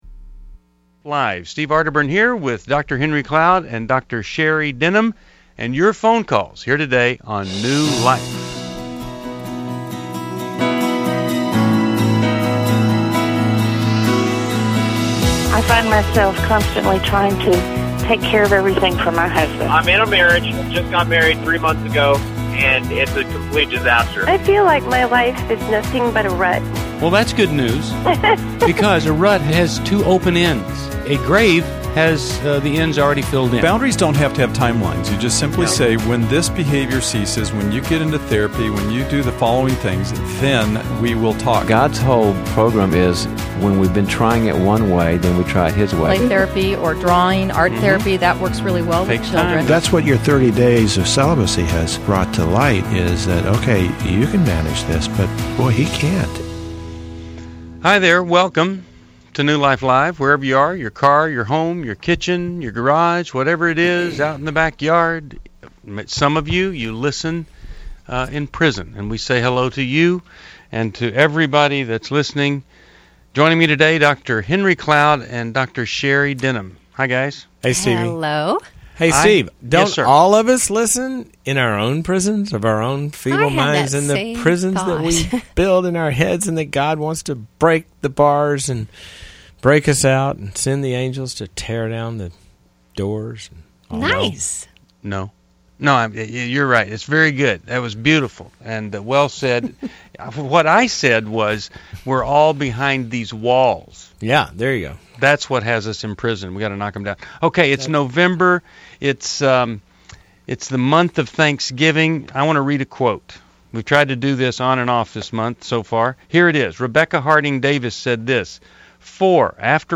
Caller Questions: What does biblically structured separation look like?